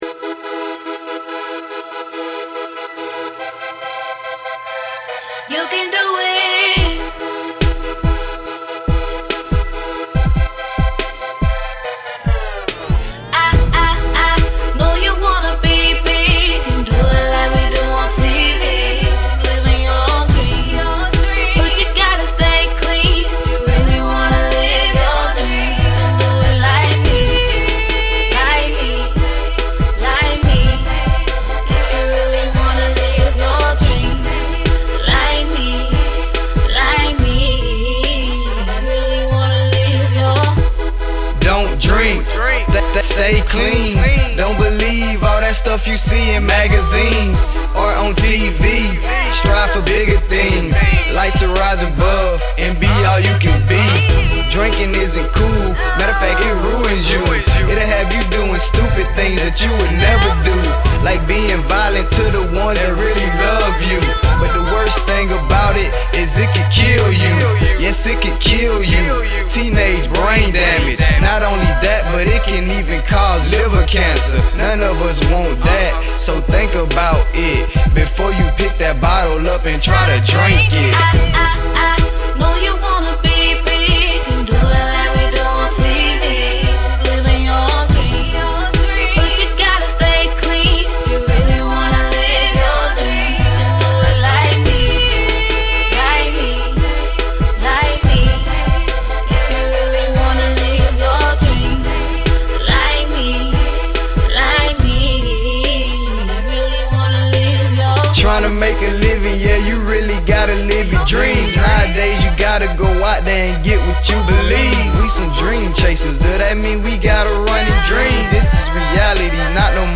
positive message student song and music video
This was a super cool project that was part of the marketing strategy we developed for a non-profit organization to raise awareness about underage drinking. We facilitated auditions for high school students to rap and sing on a song produced for us by Atlanta music producer
We selected the talent (who wrote the lyrics), performed the song at school rallies that we produced and facilitated, and professionally recorded it for a free download on the organization’s website.